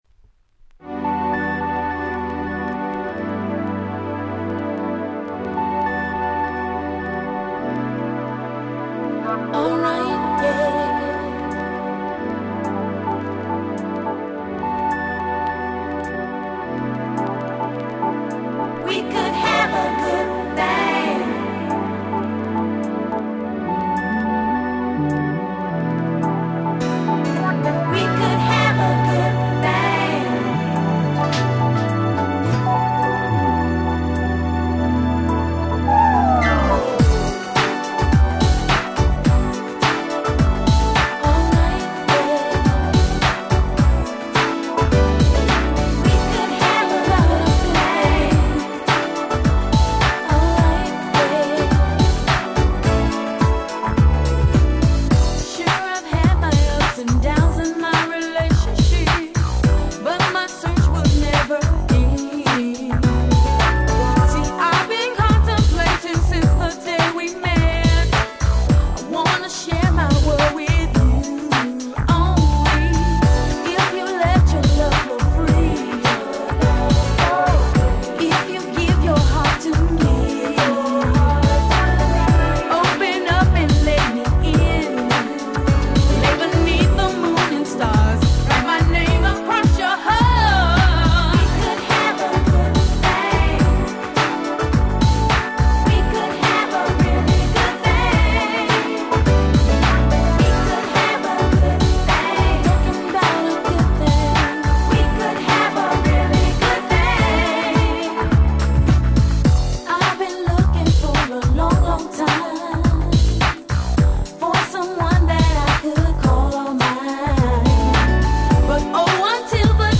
98' Nice EU R&B !!